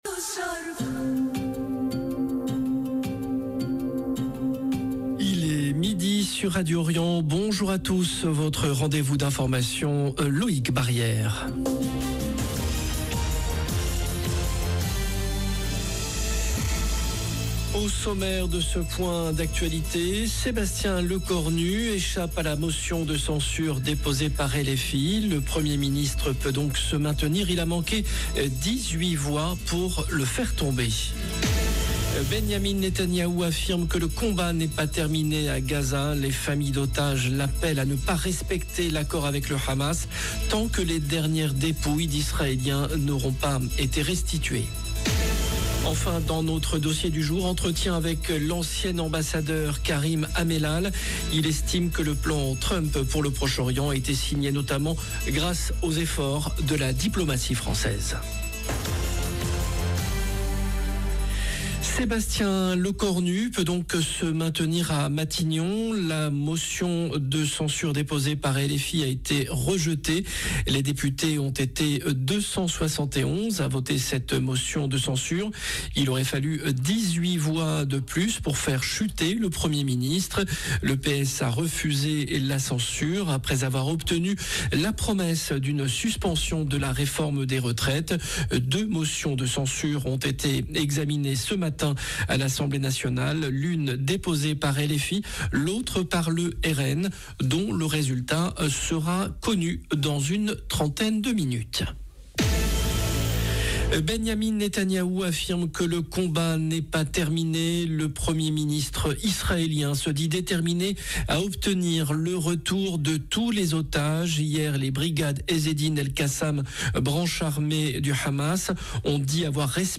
JOURNAL DE MIDI DU 16/10/2025